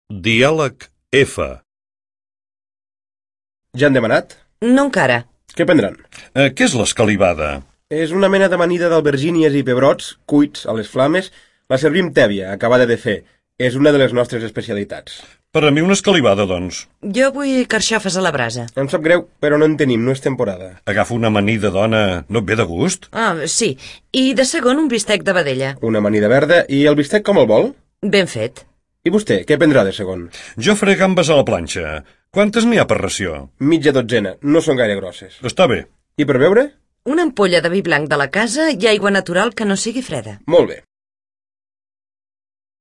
Diàleg F
Dialeg-F-el-menjar.mp3